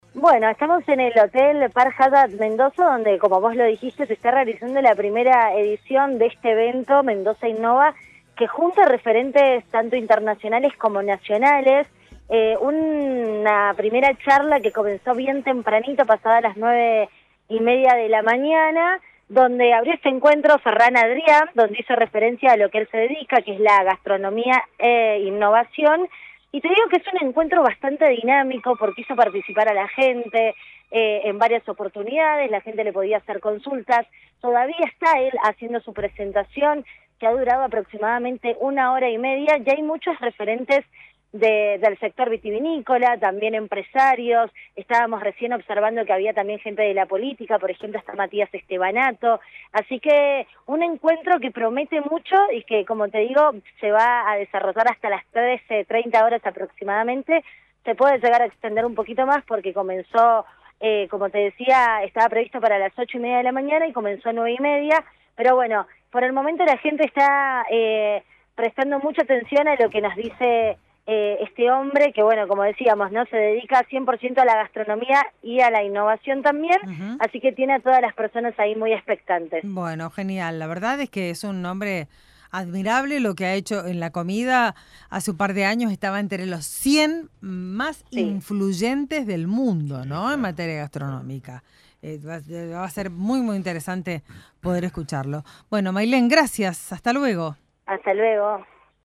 LVDiez - Radio de Cuyo - Movil de LVDiez- desde Mendoza Innova 2023